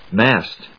/mˈæst(米国英語), mάːst(英国英語)/